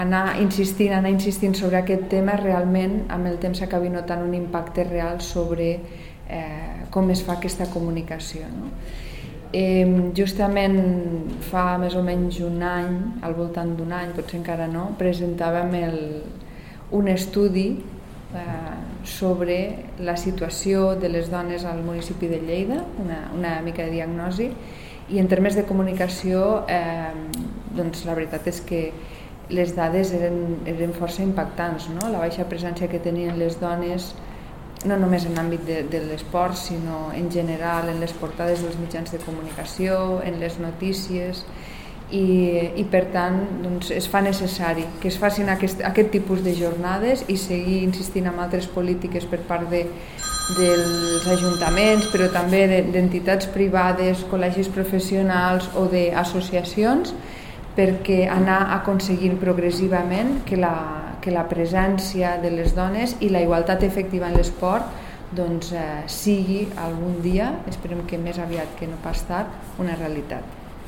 tall-de-veu-de-la-tinent-dalcalde-sandra-castro-sobre-la-iii-jornada-comunicacio-dona-i-esport